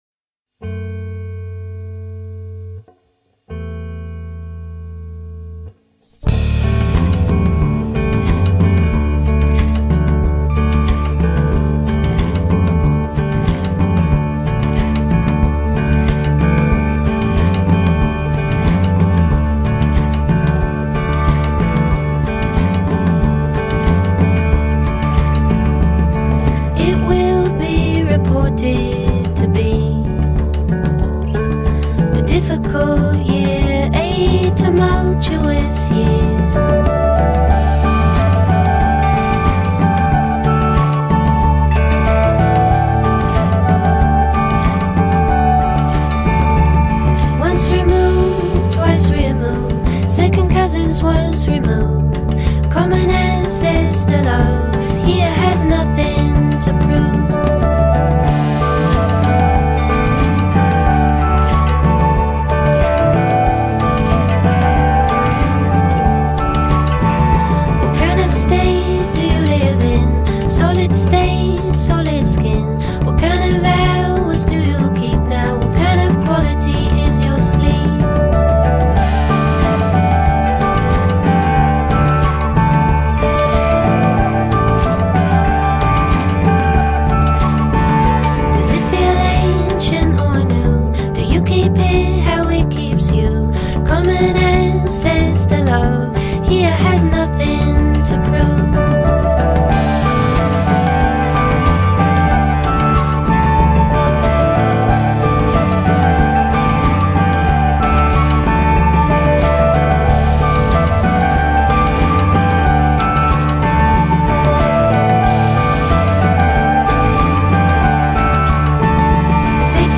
March 21, 2015 / / singer songwriter